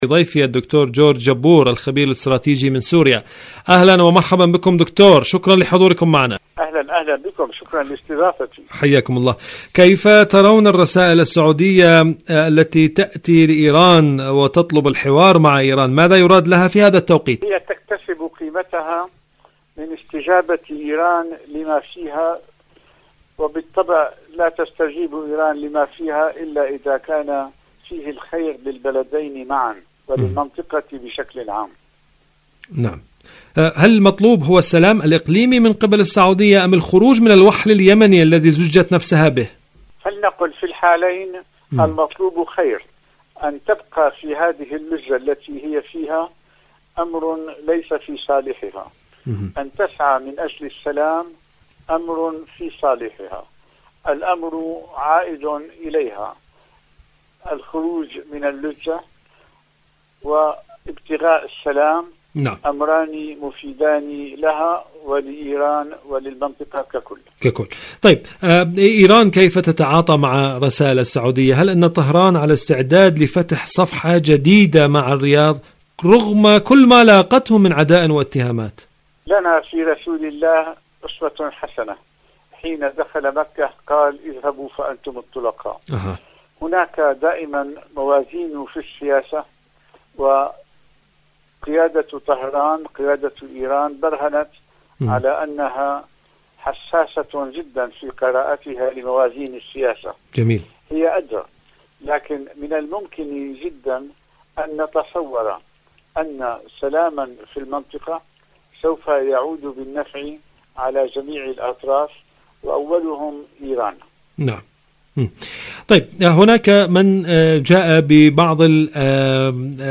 إذاعة طهران-حدث وحوار: مقابلة إذاعية